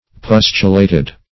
Pustulated \Pus"tu*la`ted\